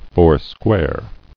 [four·square]